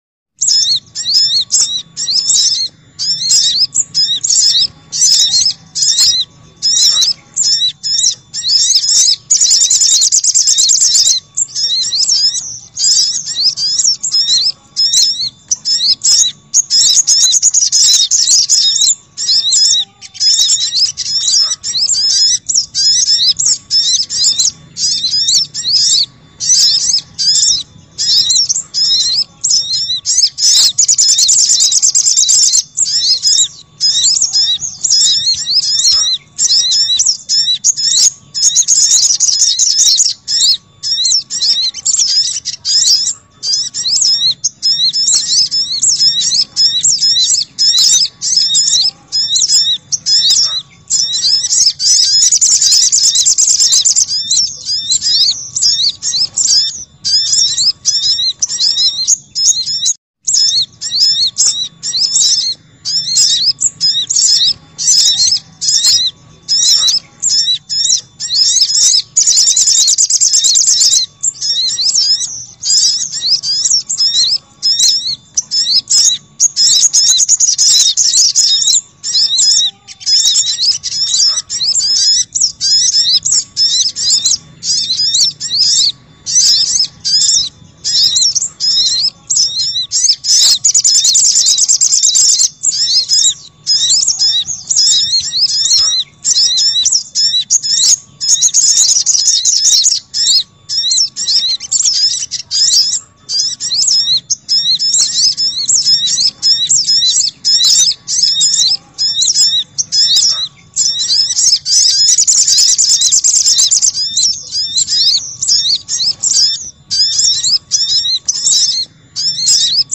Suara Burung Sogok Ontong
Kategori: Suara burung
suara-burung-sogok-ontong-id-www_tiengdong_com.mp3